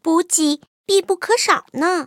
野牛II补给语音.OGG